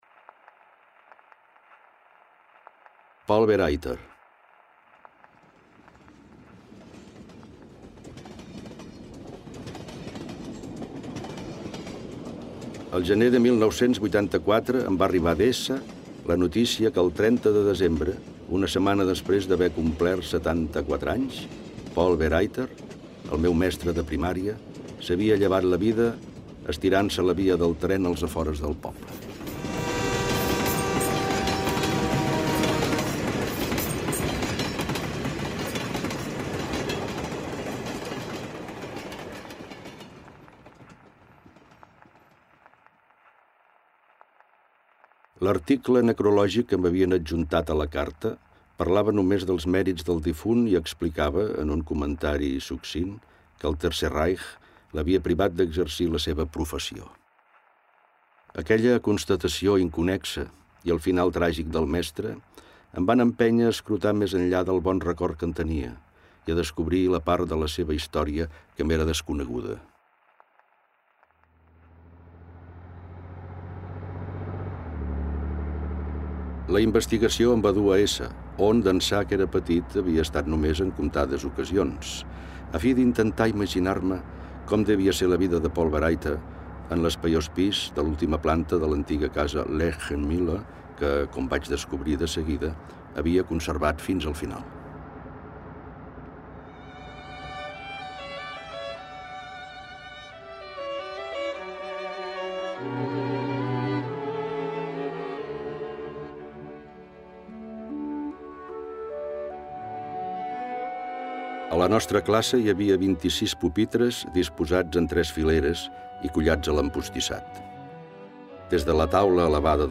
Amb aquesta idea vaig crear un espai sonor que fusiona fragments del text sebaldià amb altres materials esmentats al llibre (cançons, sorolls ambientals, pel·lícules…). L’objectiu era confegir un mapa de referències sonores que conversés poèticament amb les fotografies i, d’aquesta manera, superposar diverses capes de significat.